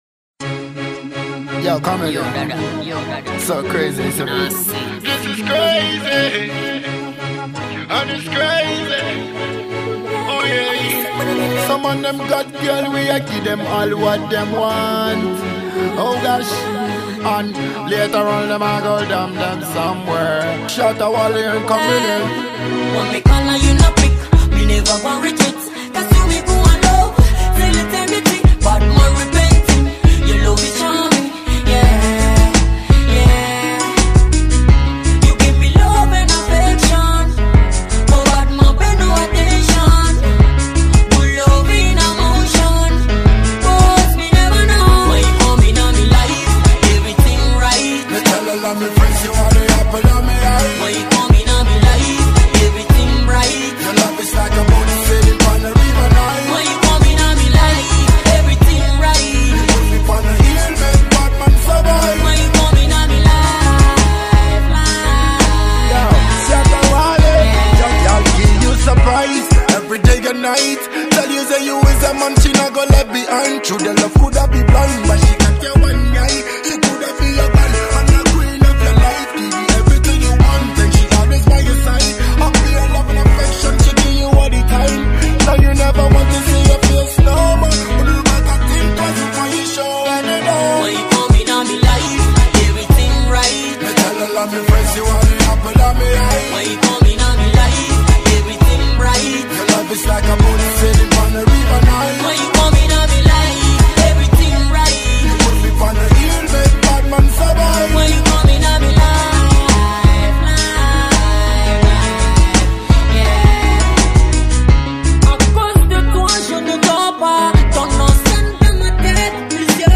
Ghanaian talented dancehall singer